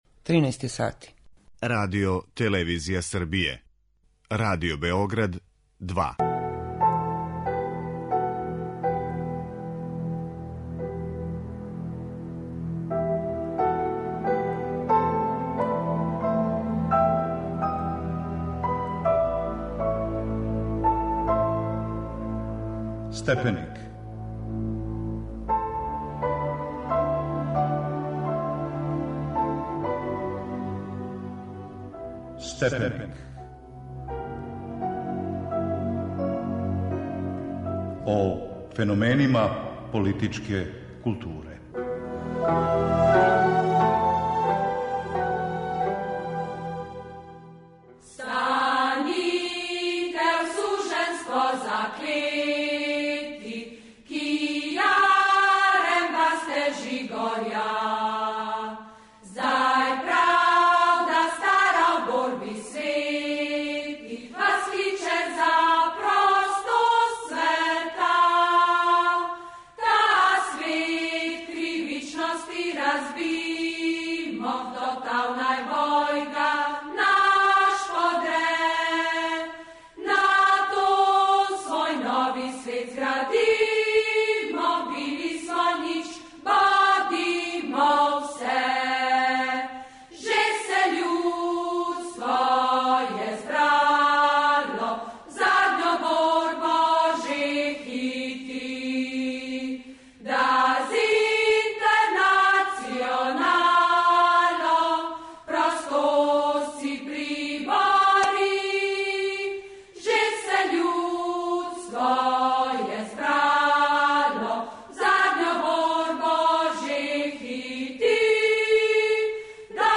а певају хор Комбинат